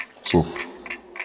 speech
speech-commands